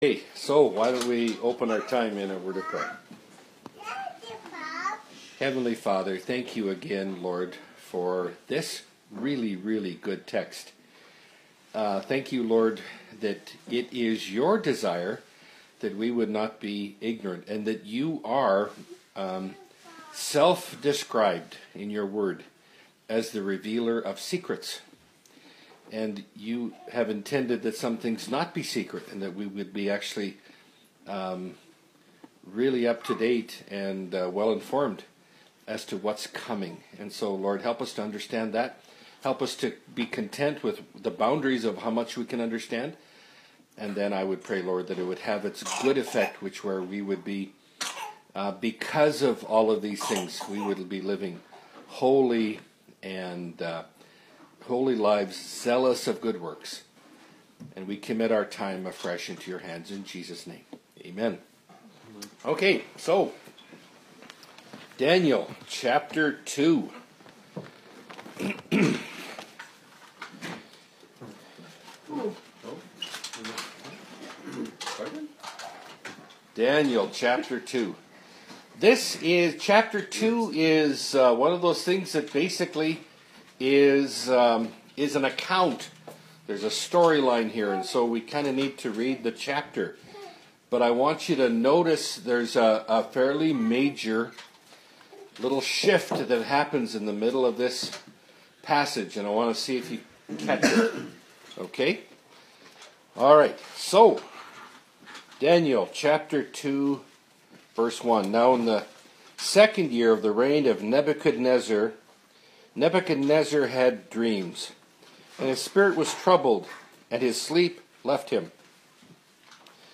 Bible Study – Daniel 2 – Part 1 (2017)